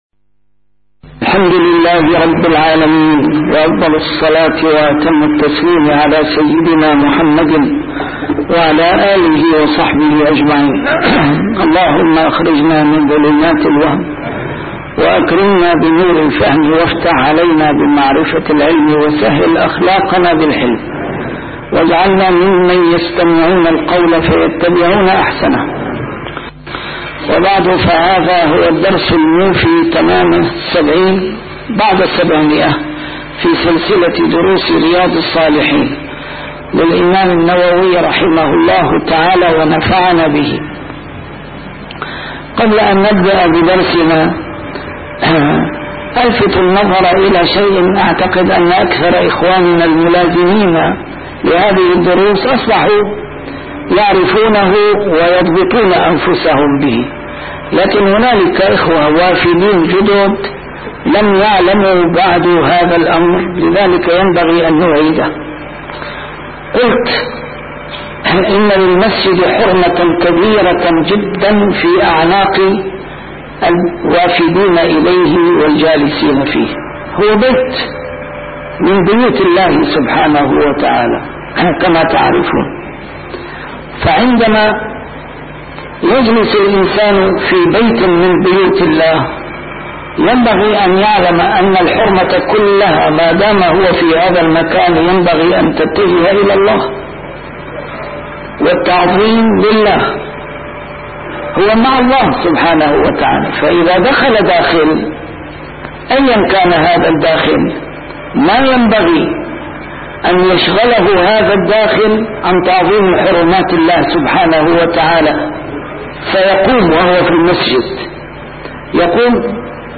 A MARTYR SCHOLAR: IMAM MUHAMMAD SAEED RAMADAN AL-BOUTI - الدروس العلمية - شرح كتاب رياض الصالحين - 770- شرح رياض الصالحين: فضل صلاة الجماعة